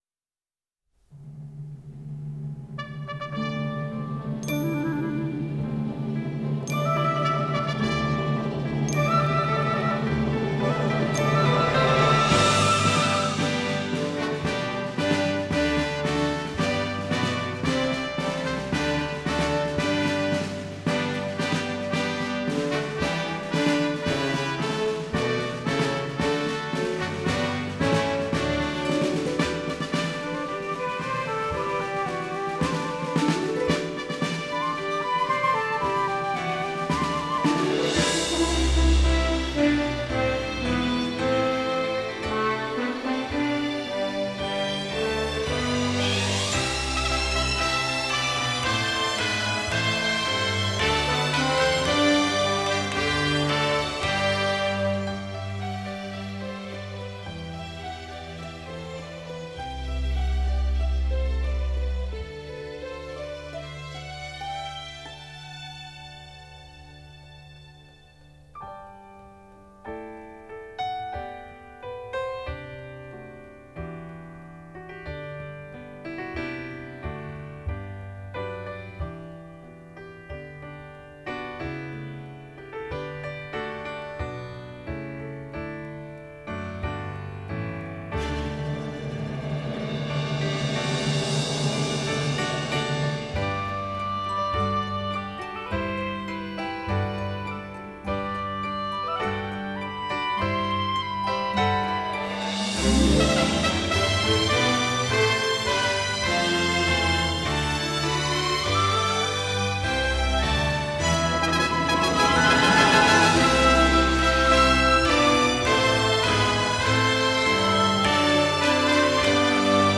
Hark the Herald Angels Sing (High Key – G – Performance backing track) | Ipswich Hospital Community Choir